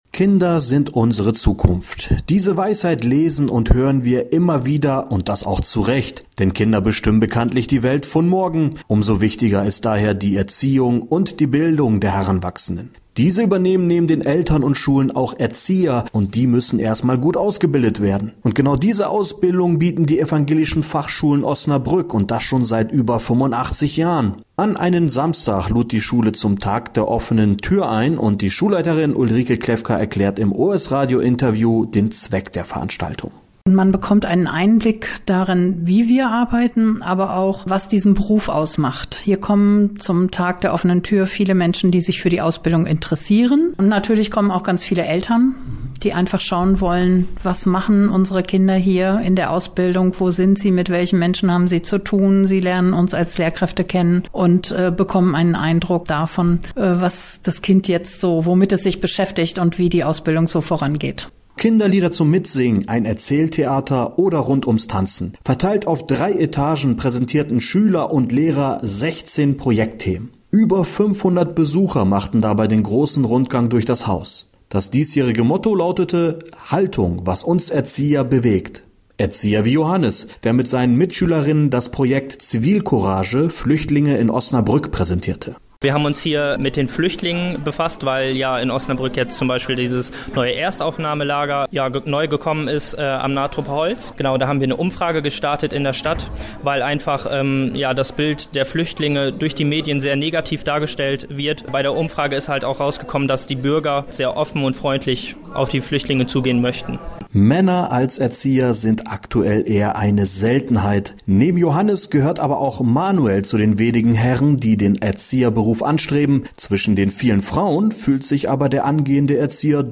mp3 Mitschnitt os-radio (104,8) zum Tag der Offenen Tür
radiobeitrag-offene_tuer2015